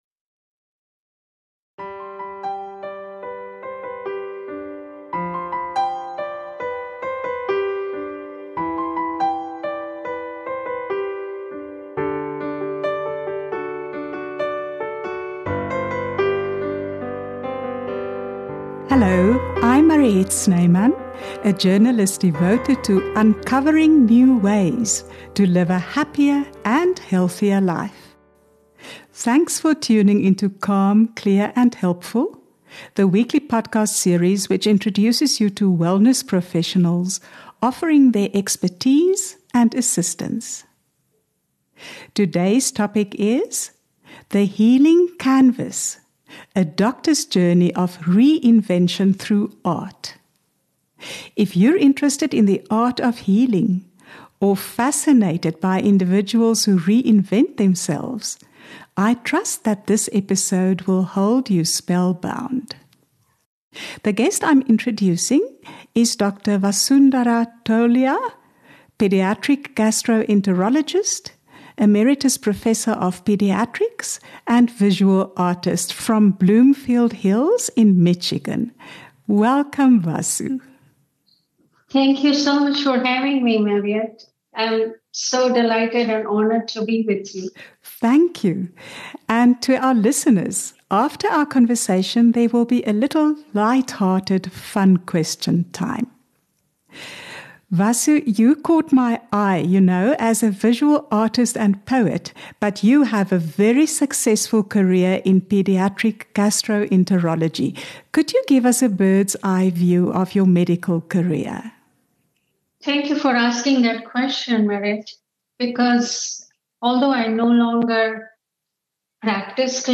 interviews a range of experts on holistic health, love relationships, parenting, and life's phases and challenges.